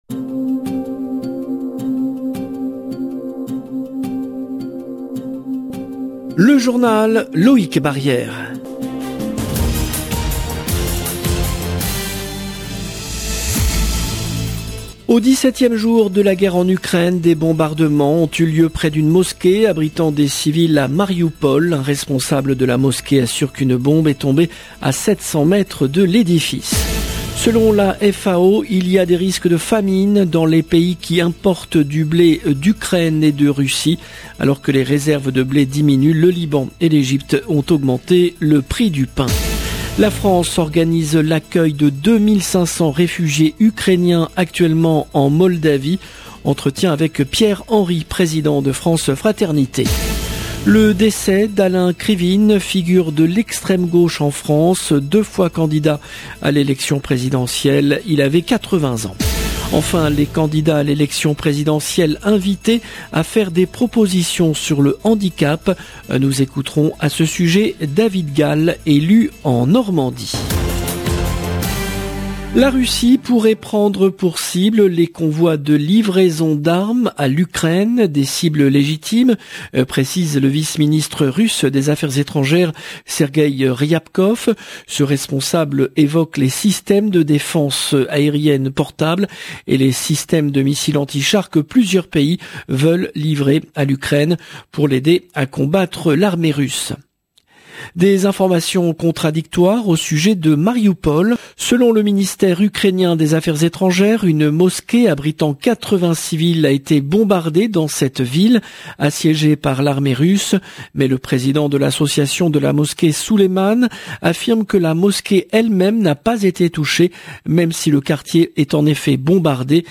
LE JOURNAL DU SOIR EN LANGUE FRANCAISE